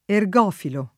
[ er g0 filo ]